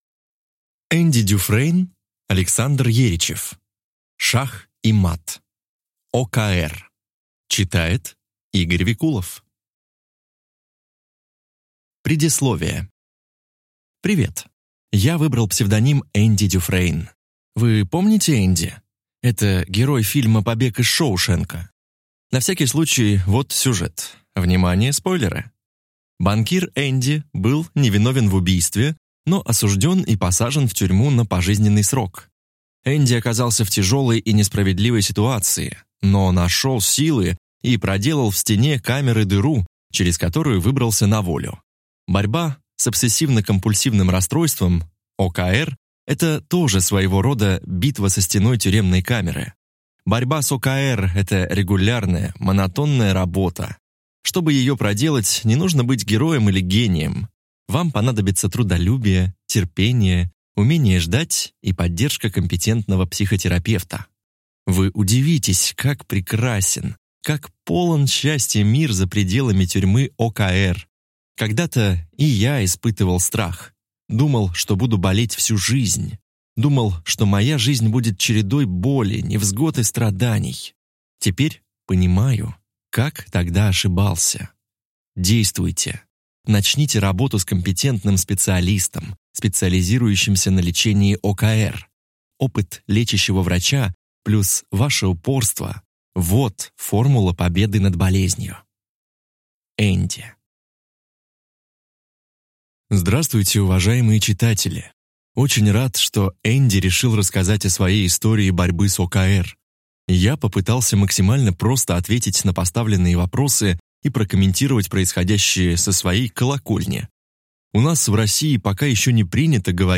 Аудиокнига Шах и мат, ОКР | Библиотека аудиокниг